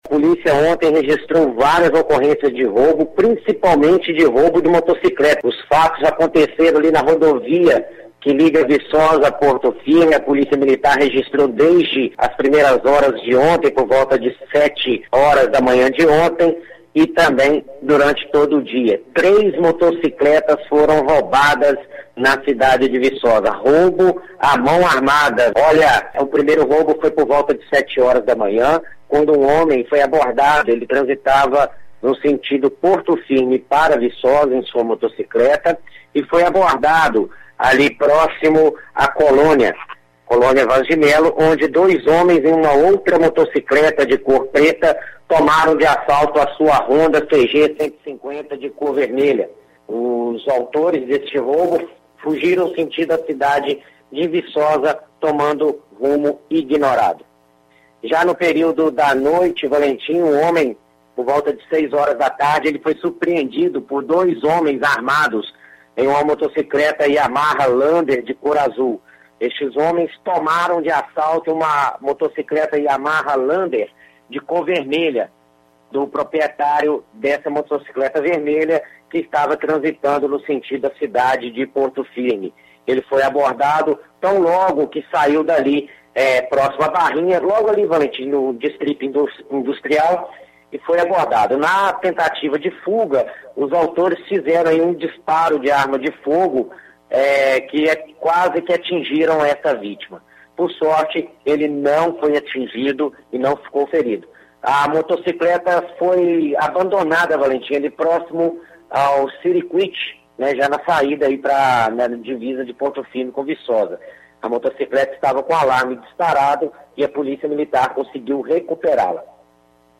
notícias-policiais-quinta-feira-06-07.mp3